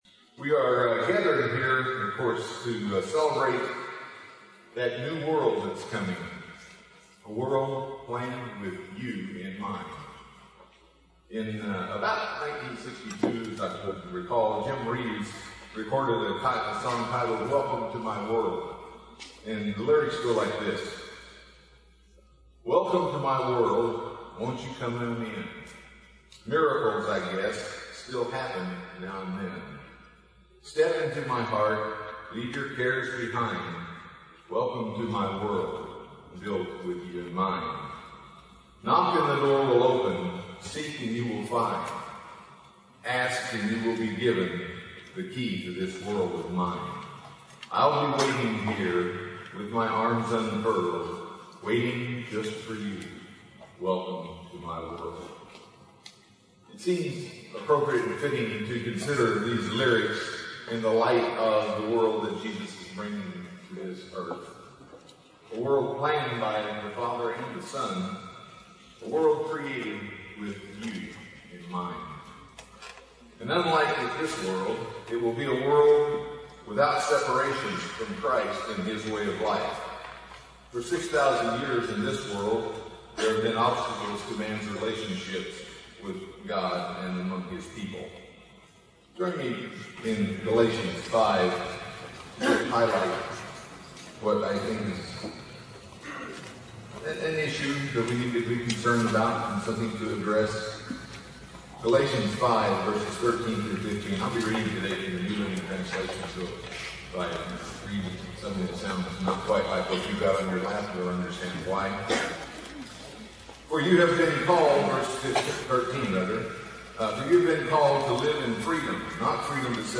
This sermon was given at the Oceanside, California 2018 Feast site.